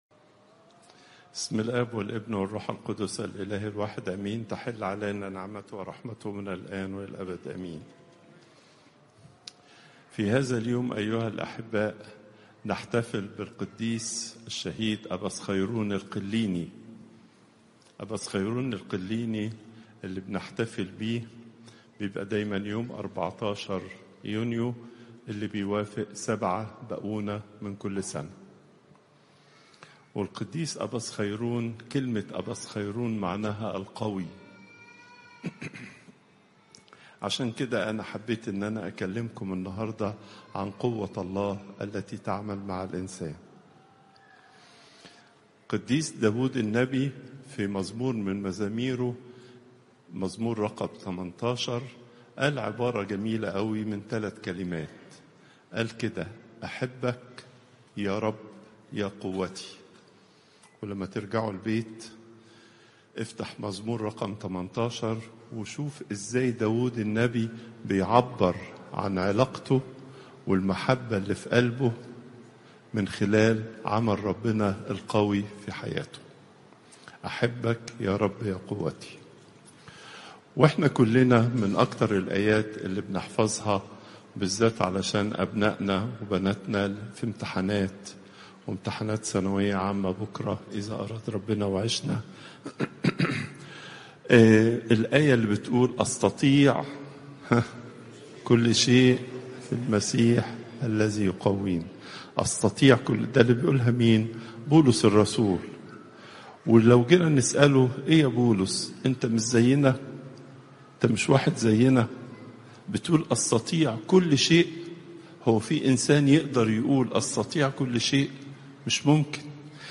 Popup Player تحميل الصوت البابا تواضروس الثانى السبت، 14 يونيو 2025 27:48 المحاضرة الأسبوعية لقداسة البابا تواضروس الثاني الزيارات: 248